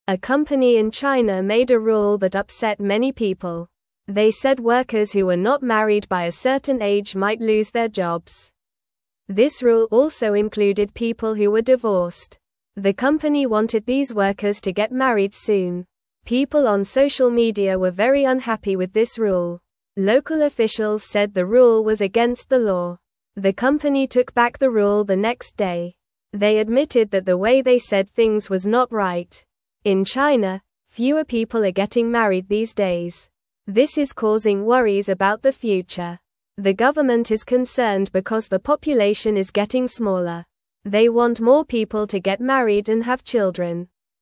Normal
English-Newsroom-Lower-Intermediate-NORMAL-Reading-Company-Makes-Workers-Marry-or-Lose-Their-Jobs.mp3